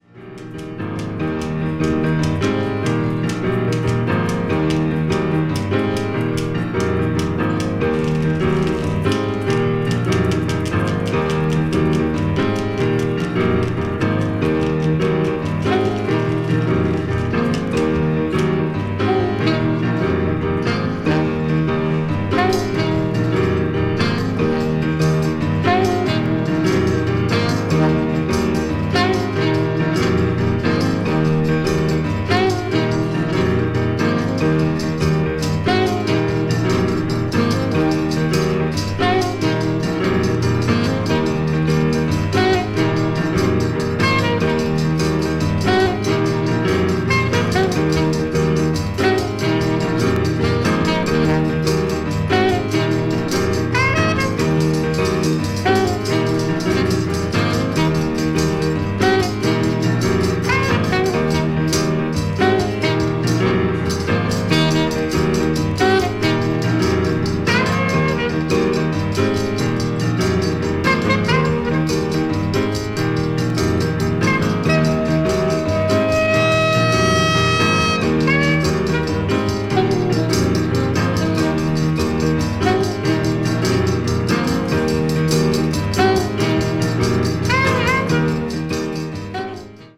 contemporary jazz   ethnic jazz   free jazz